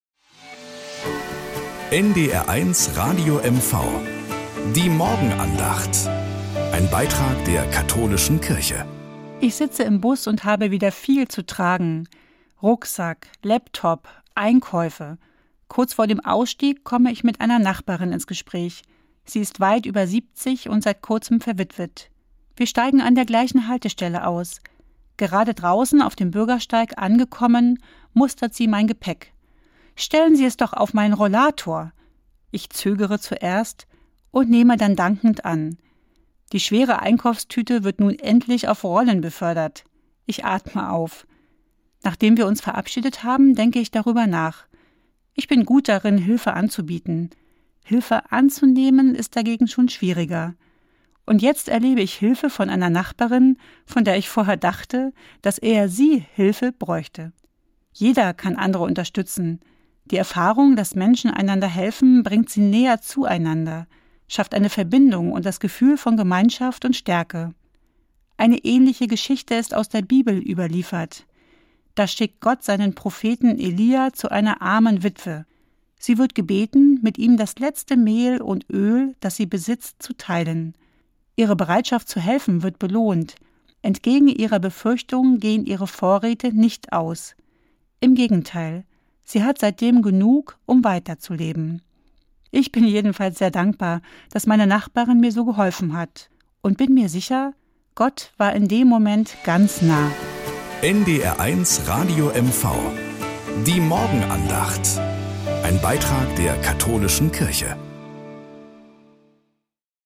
Nachrichten aus Mecklenburg-Vorpommern - 26.04.2025